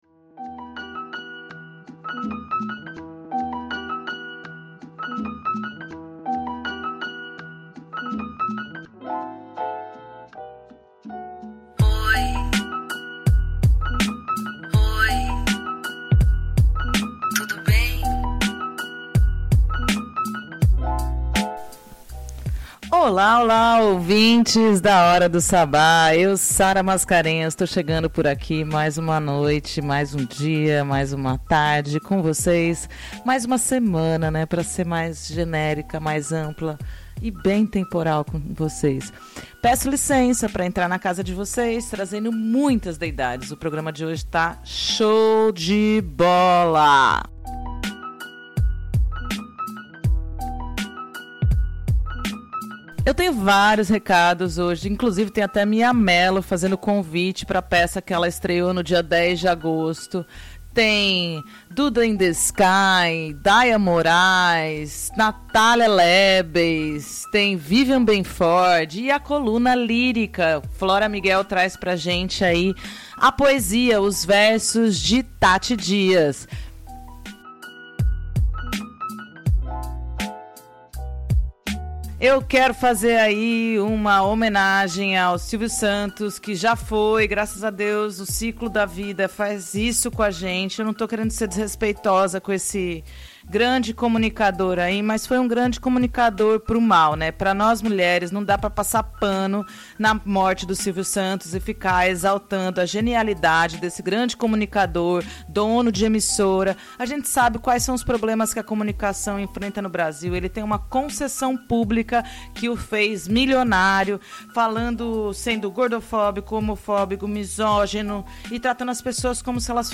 Na 20ª edição do Hora do Sabbat, a parte musical está imperdível!